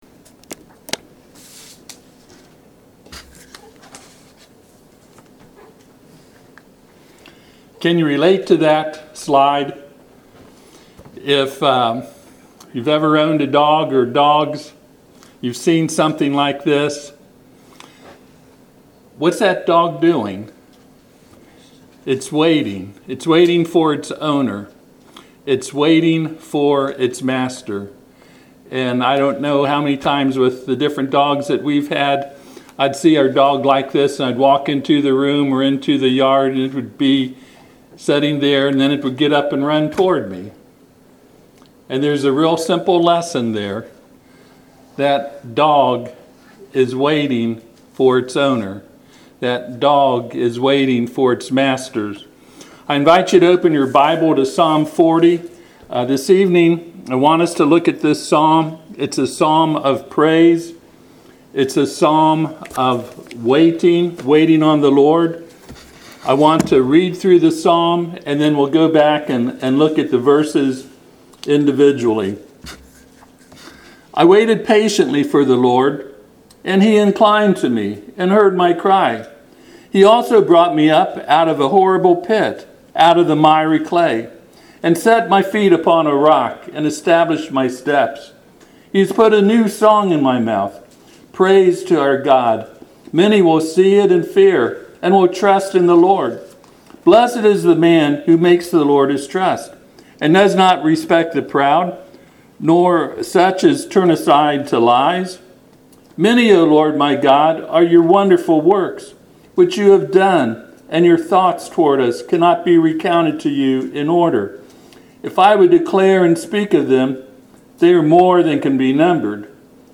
Psalm 40:1 Service Type: Sunday PM https